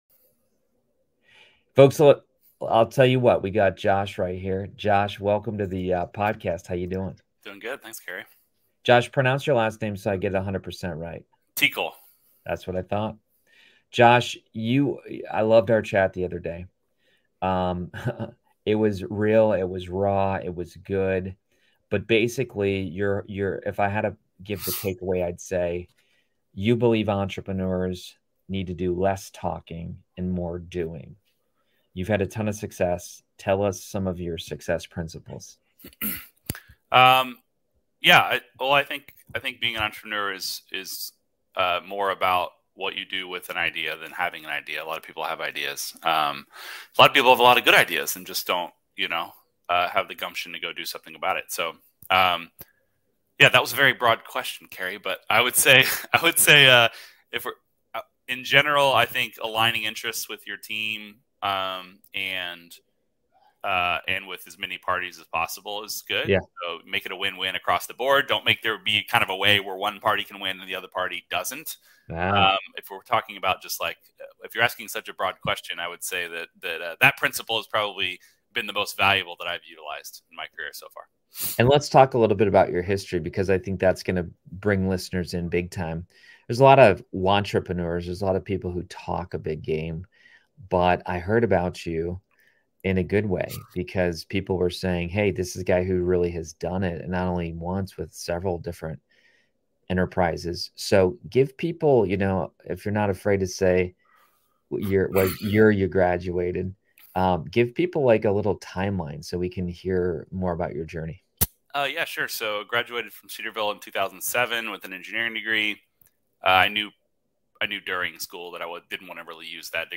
Tune in for a wild conversation.